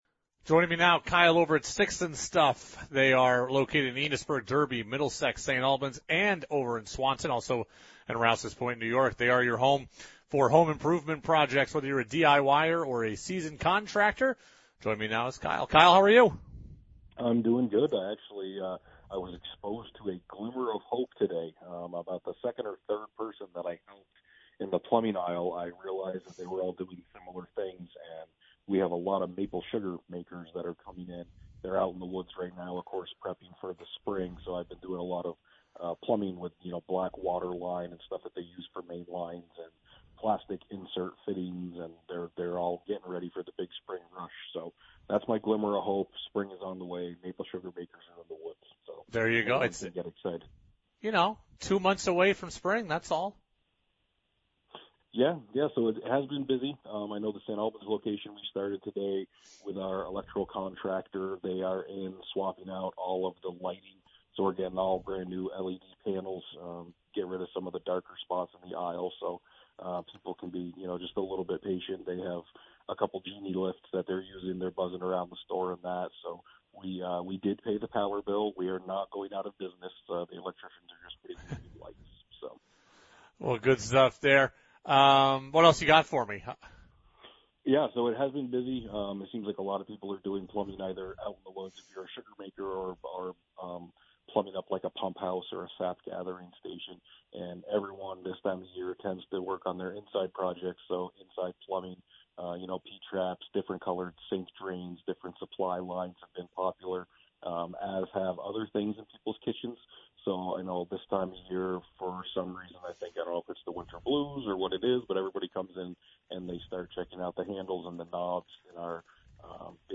live on WDEV and Radio Vermont Group.